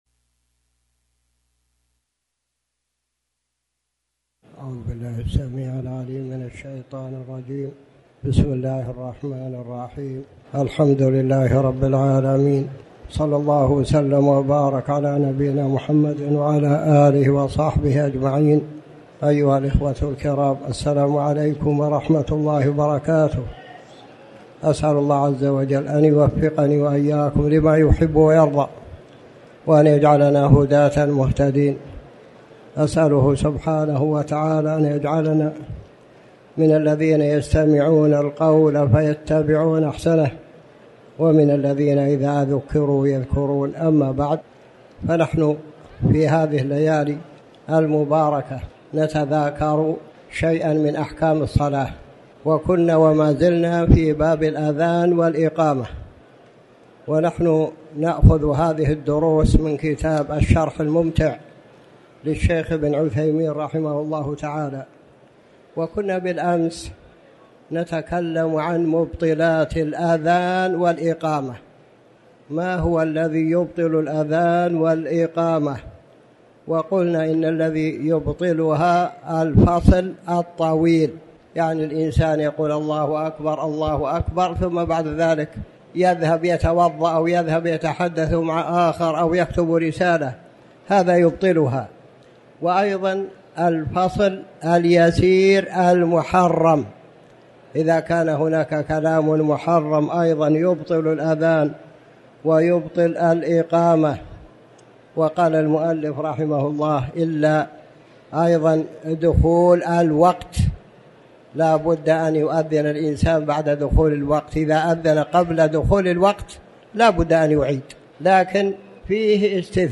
تاريخ النشر ١٥ صفر ١٤٤٠ هـ المكان: المسجد الحرام الشيخ